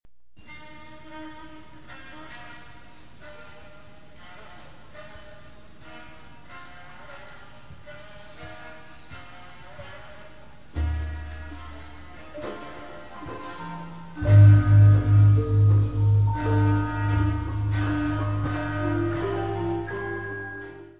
Música etnica de las islas del Indico : Java : javanese court gamelan
: stereo; 12 cm
em Jogyakarta, no "Pendopo" de Pura Paku Alaman
Music Category/Genre:  World and Traditional Music